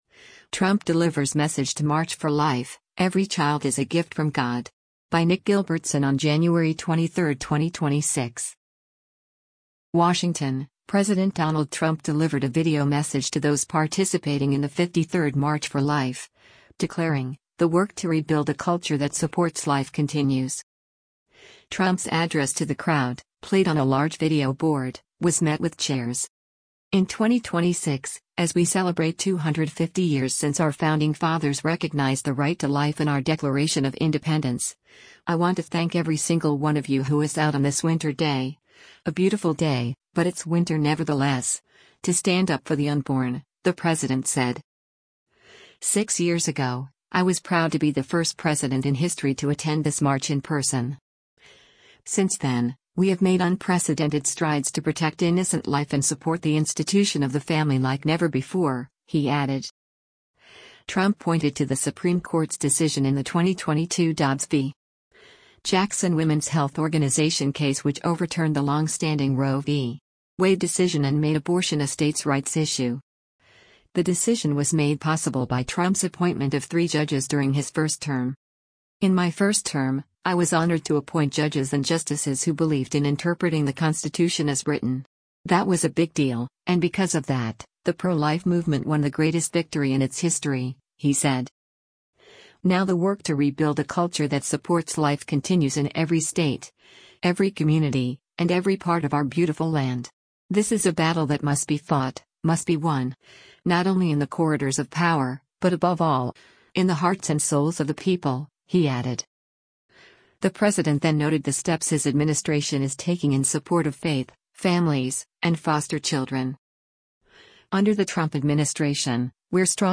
WASHINGTON—President Donald Trump delivered a video message to those participating in the 53rd March for Life, declaring, “The work to rebuild a culture that supports life continues.”
Trump’s address to the crowd, played on a large video board, was met with cheers.